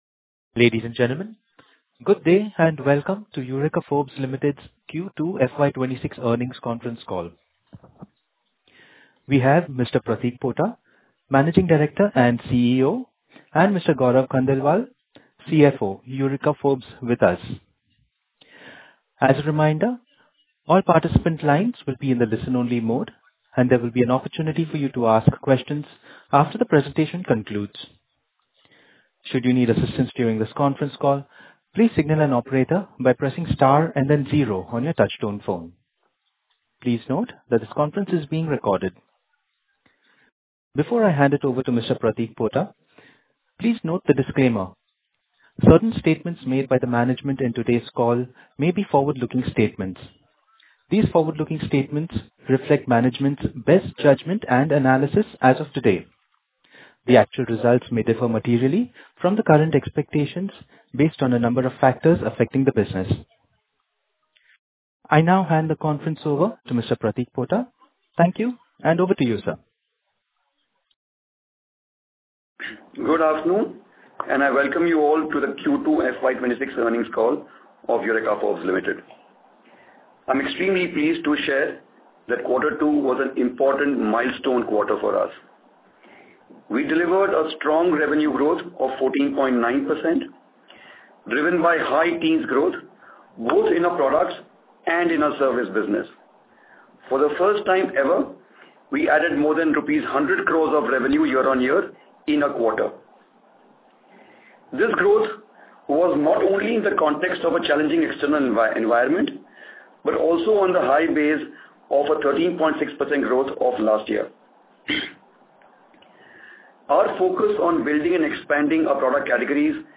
Concalls
Analysts_and_Earnings_Call_Q2FY26.mp3